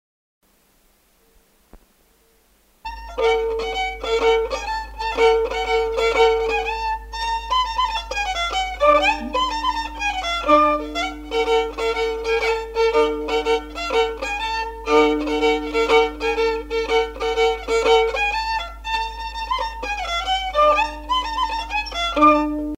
Aire culturelle : Lomagne
Lieu : Garganvillar
Genre : morceau instrumental
Descripteurs : rondeau
Instrument de musique : violon